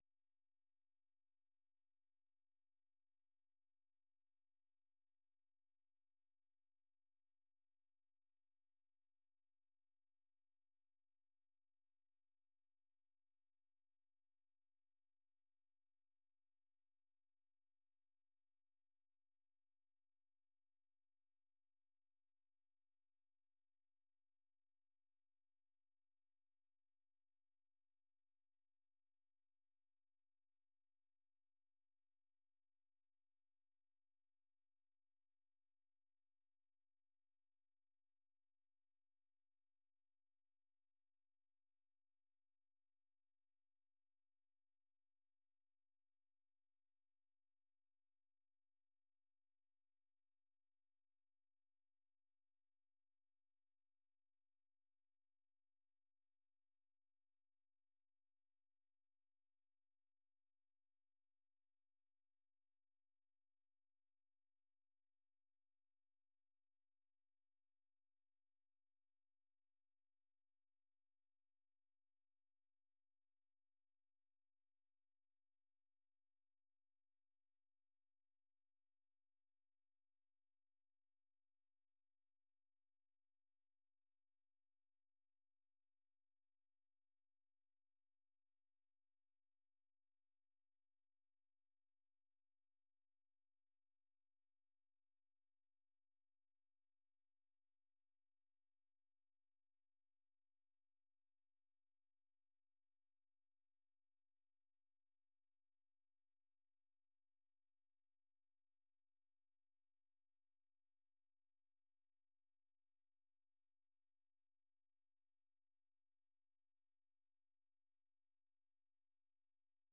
세계 뉴스와 함께 미국의 모든 것을 소개하는 '생방송 여기는 워싱턴입니다', 저녁 방송입니다.